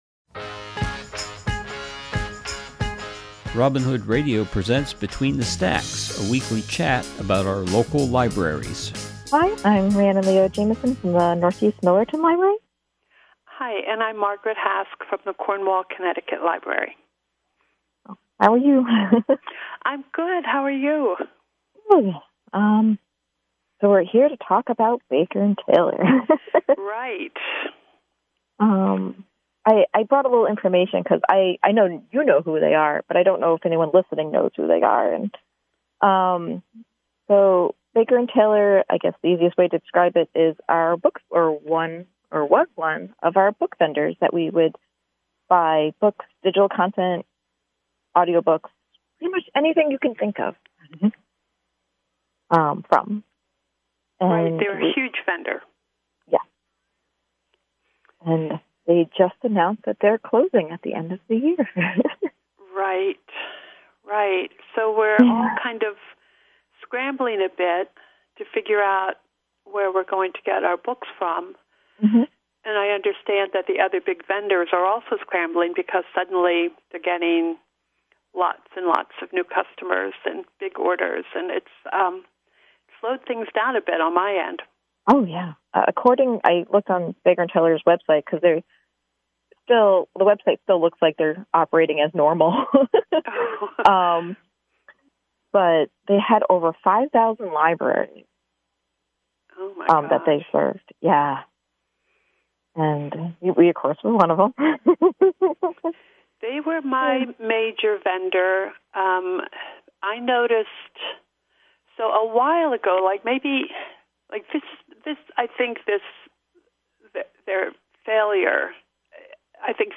This program is a conversation